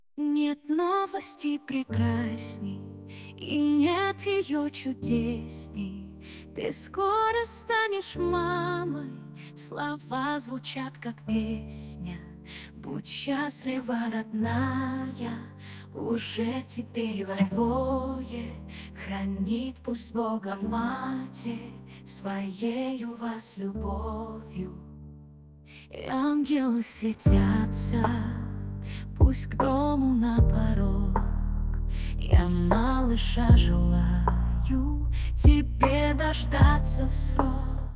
Мелодии на гендер пати, фоновая музыка, песни, демо записи: